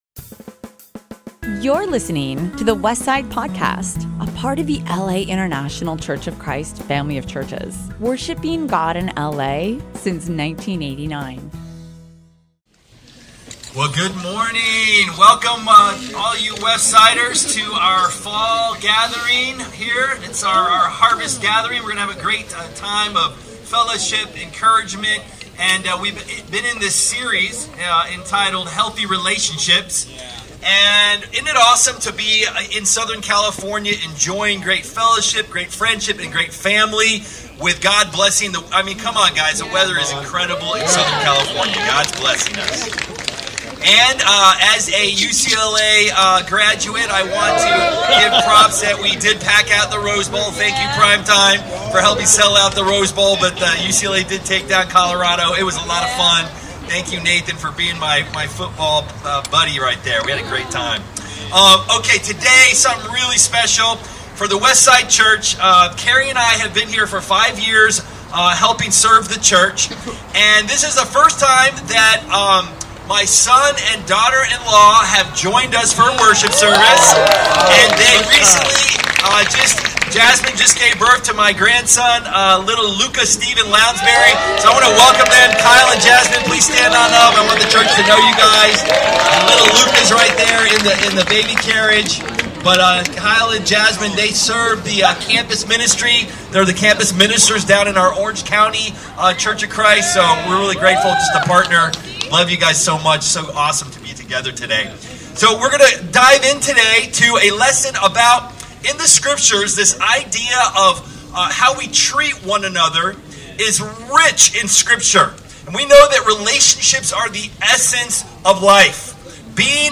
Outdoor Service